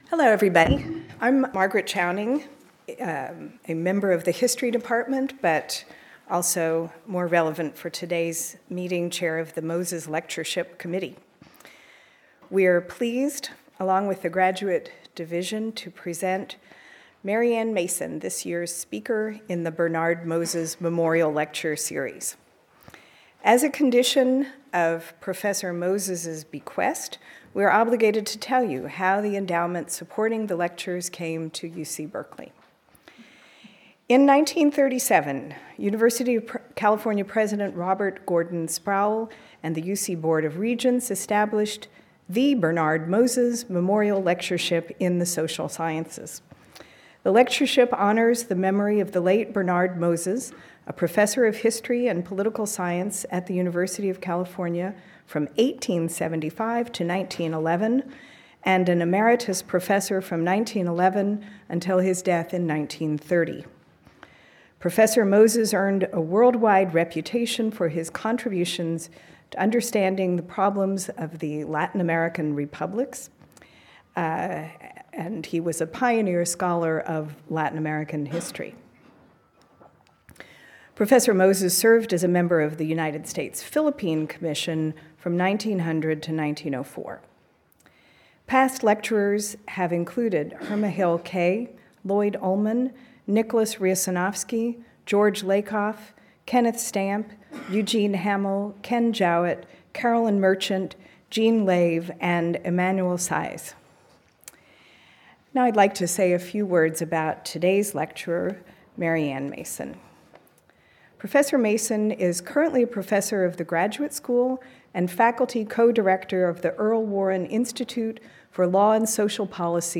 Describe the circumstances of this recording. Continued Alumni House, Toll Room - UC Berkeley Campus Berkeley Graduate Lectures [email protected] false MM/DD/YYYY